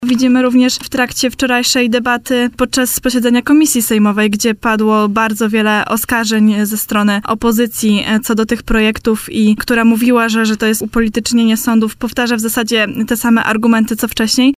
w poranku „Siódma9” na antenie Radia Warszawa